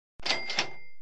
sound-effect-cash-register.mp3